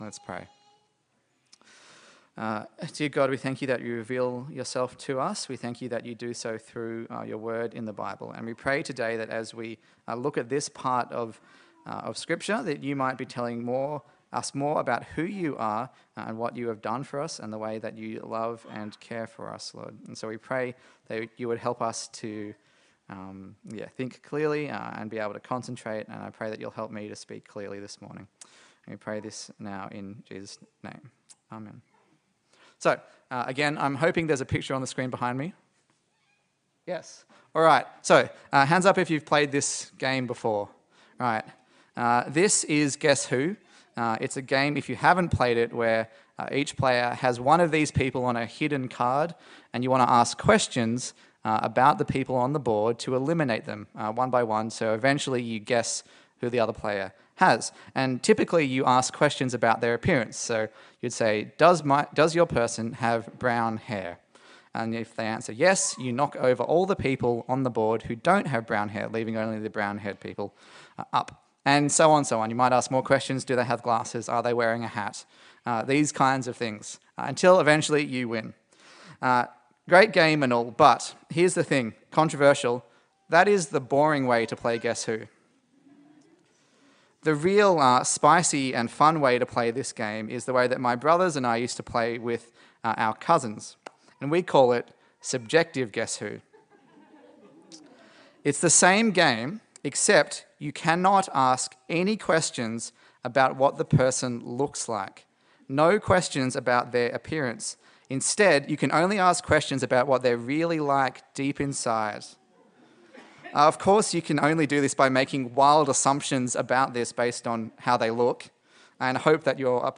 A sermon in the series on the Gospel of Luke